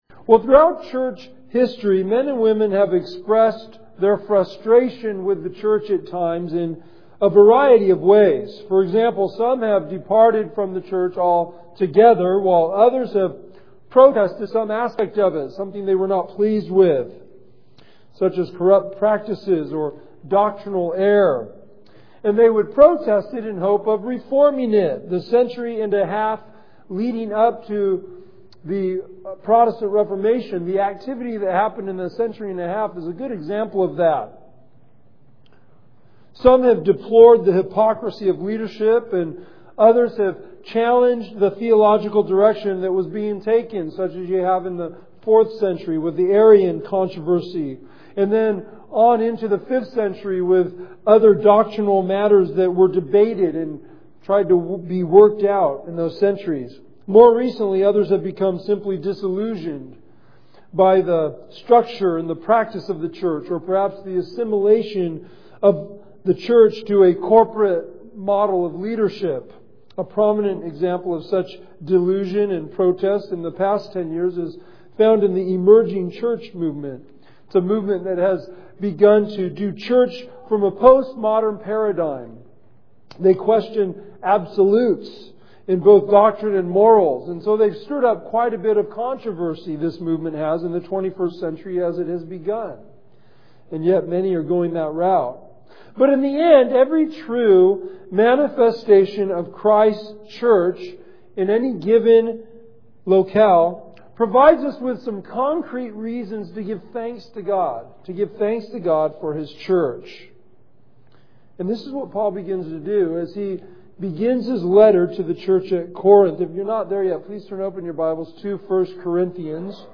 Faith Bible Church Sunday Sermon Study Notes QUESTIONS FOR 1 CORINTHIANS 1:1-9 Instructions: Read the verses and then prayerfully answer the following questions 1.